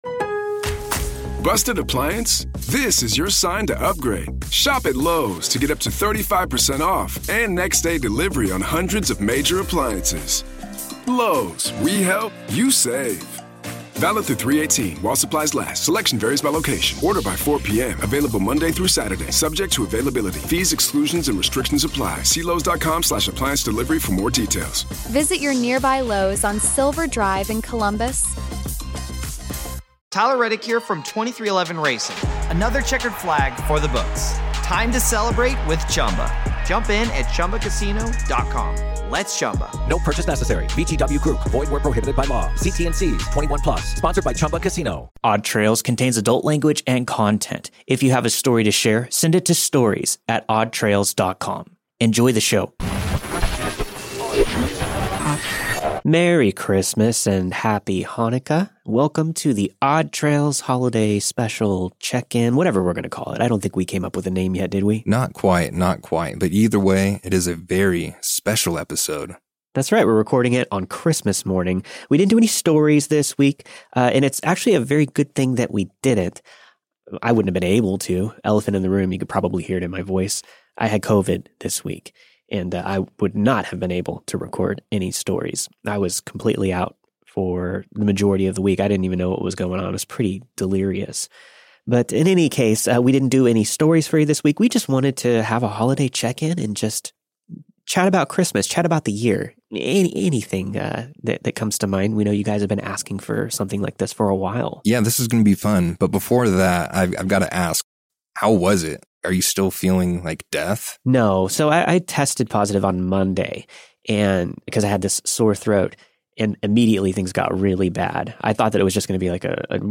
We’re celebrating the holidays by doing things a little differently this episode. The stories will be back next year, but for now, join us for a Christmas morning chat.